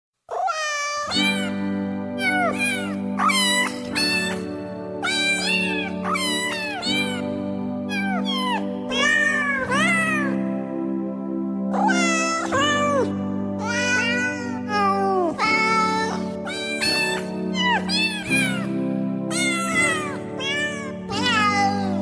• Качество: 128, Stereo
смешные
мяуканье
звук кошки
мурчание